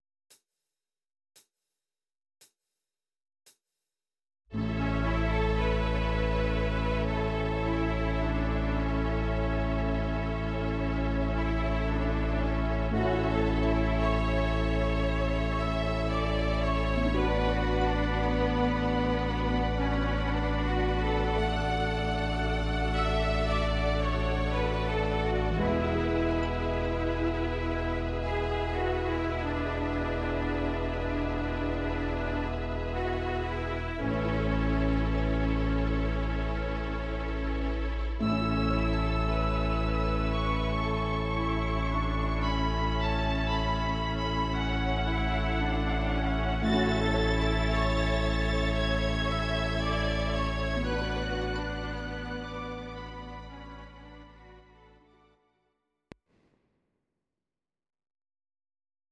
Audio Recordings based on Midi-files
Instrumental, Traditional/Folk